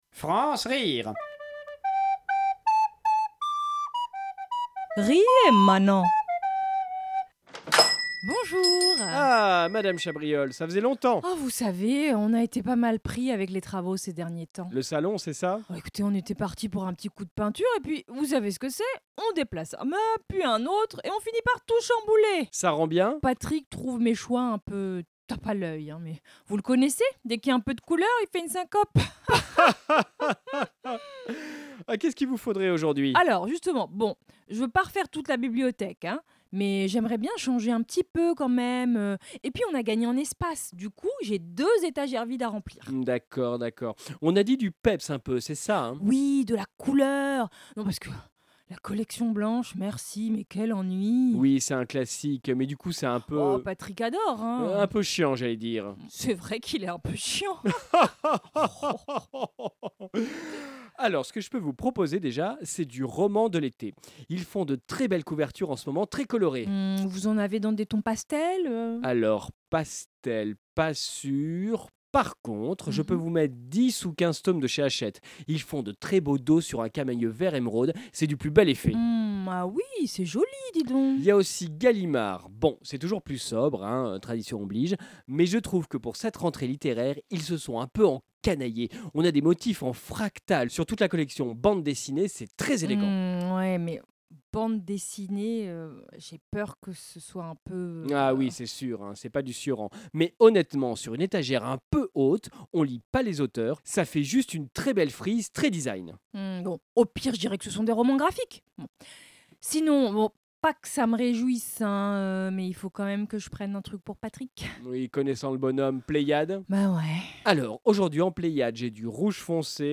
Création sonore